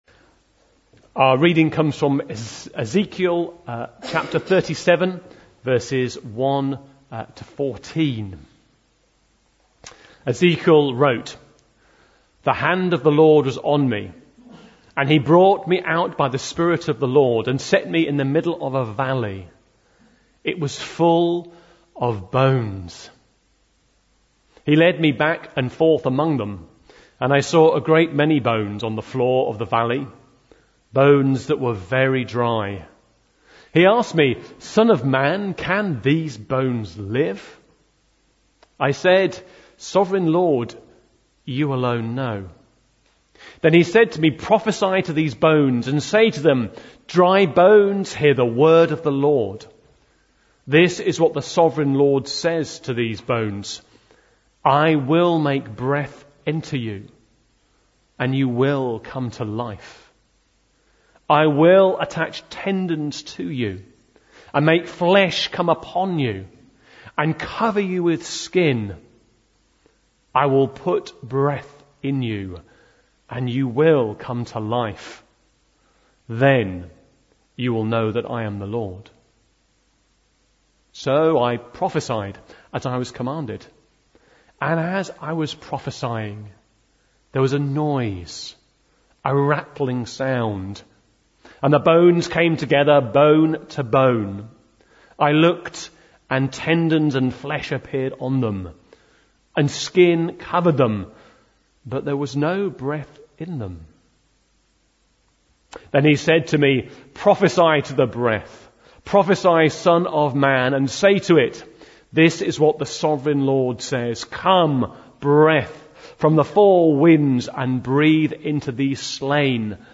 Bluntisham Baptist Church Sermons